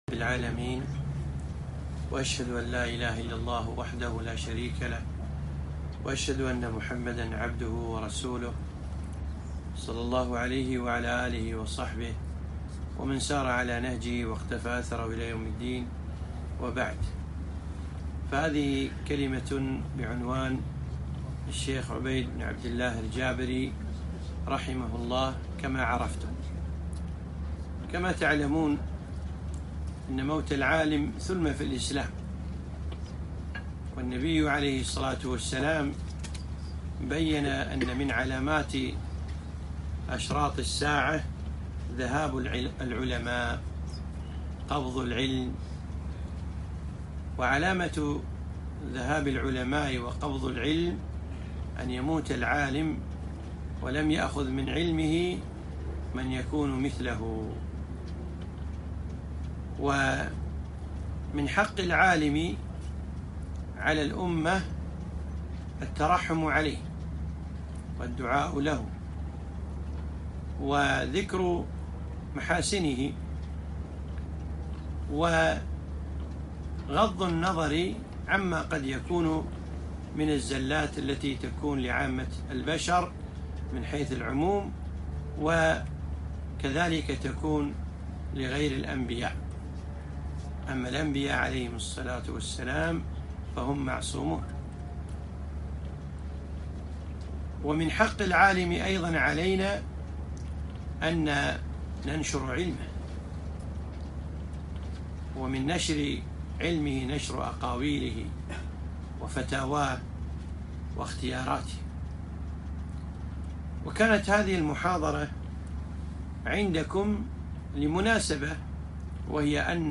محاضرة - الشيخ عبيد الجابري كما عرفته...